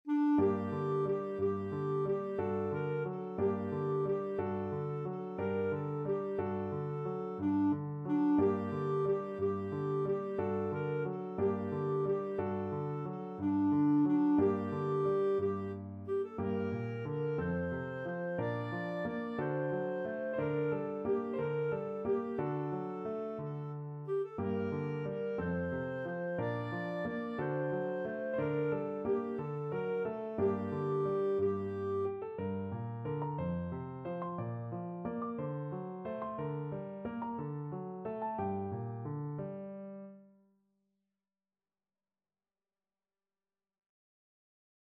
Classical Beethoven, Ludwig van Marmotte (8 Lieder, Op. 52, No. 7) Clarinet version
~ = 90 Munter
G minor (Sounding Pitch) A minor (Clarinet in Bb) (View more G minor Music for Clarinet )
6/8 (View more 6/8 Music)
D5-D6
Clarinet  (View more Easy Clarinet Music)
Classical (View more Classical Clarinet Music)